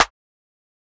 Clap (Sexy).wav